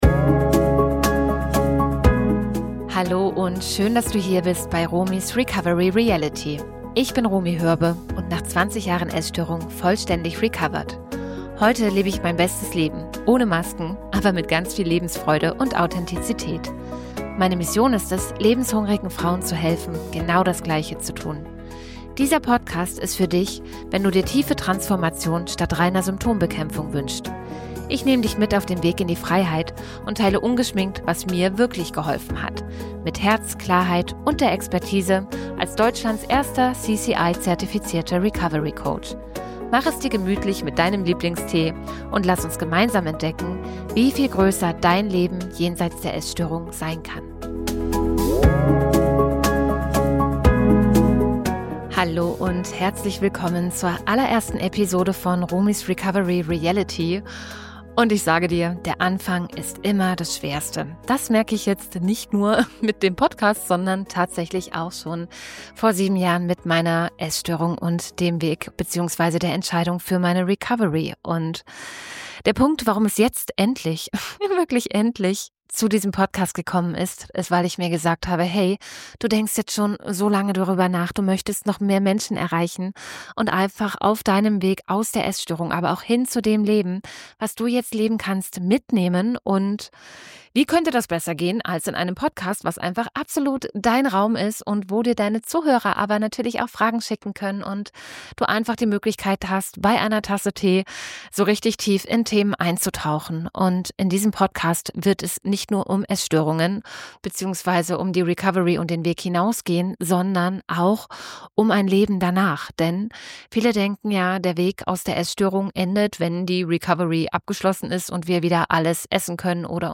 Von emotionalem Essen in meiner Grundschulzeit über Magersucht, Bulimie bis hin zur Orthorexie – ich spreche ungeschminkt über meinen Weg zur vollständigen Heilung. Mit einer Tasse Tee sitze ich hier auf meiner Couch in Dubai, blicke auf einen wunderschönen grünen Park und kann kaum glauben, wie weit mich mein Weg gebracht hat.